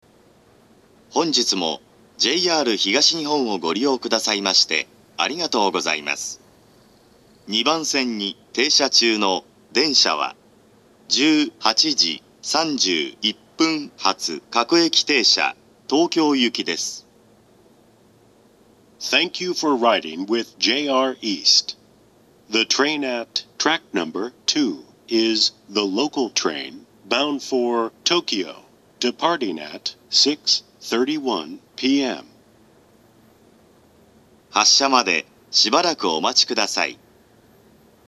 ２番線出発予告放送
発車メロディー（ＪＲＥ-ＩＫＳＴ-０0１-0１）
なお、到着放送の鳴動は非常に遅く、停車時間がない電車だと確実に被ります。
また、良い収録場所がないのも難点です。